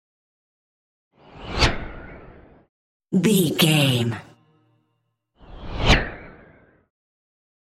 Whoosh bright airy fast x2
Sound Effects
Fast
bright
futuristic
whoosh